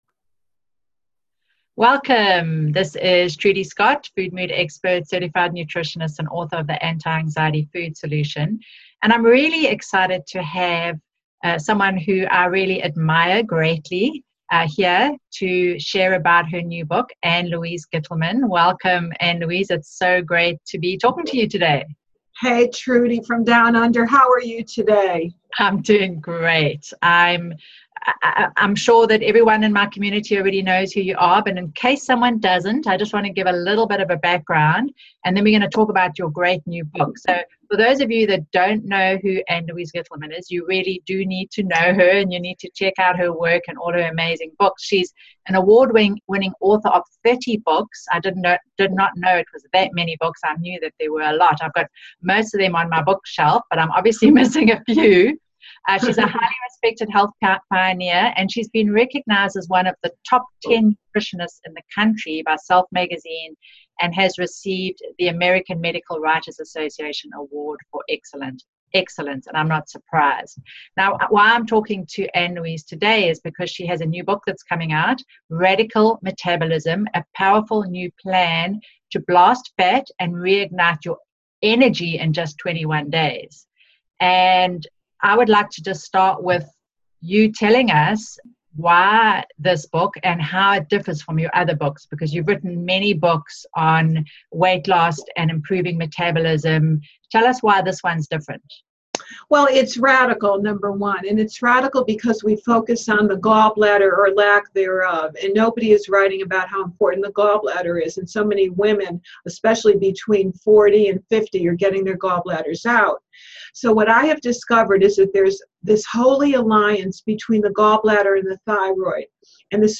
ann-louise-gittleman-radical-matabolism-interview.mp3